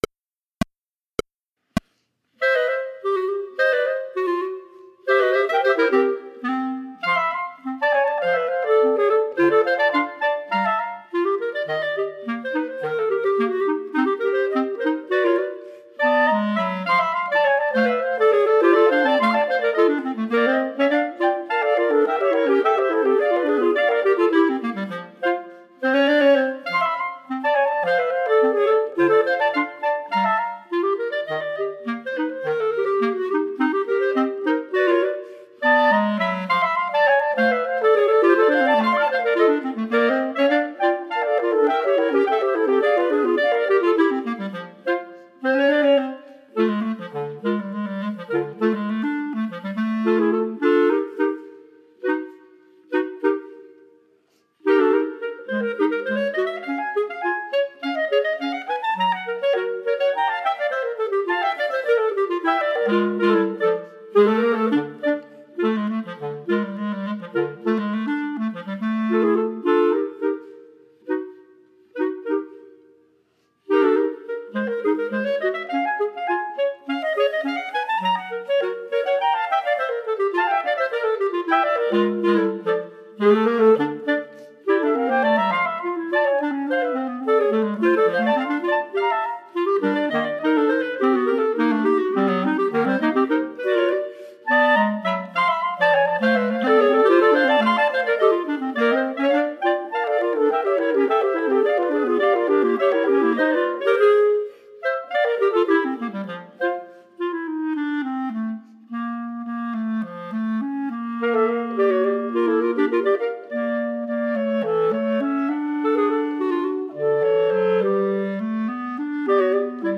Medium Tempo, minus 3rd part